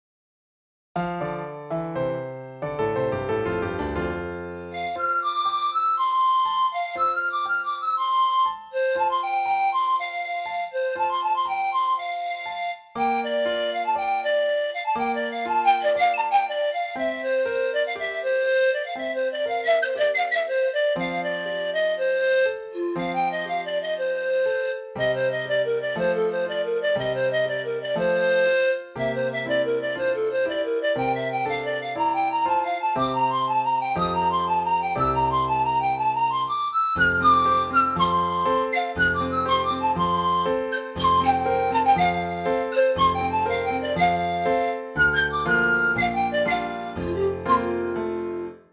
Dabei handelt es sich um eine Nai, aus Rumänien und eine Toyo Bass Pan Flöte aus den Anden.